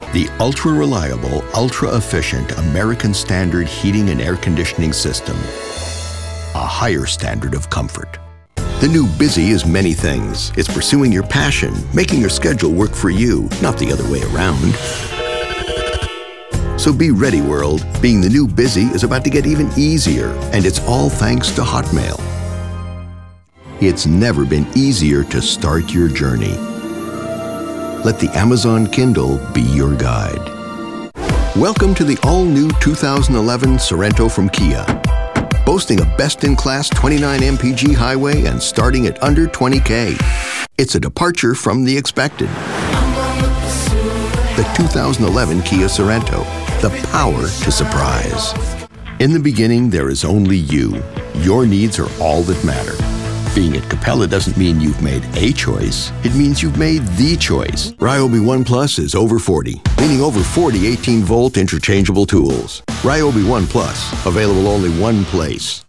Middle/older age, American male with a mid-range voice. Friendly, warm, informative, technical.
englisch (us)
Sprechproben: